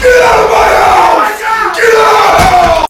get out my house Meme Sound Effect
This sound is perfect for adding humor, surprise, or dramatic timing to your content.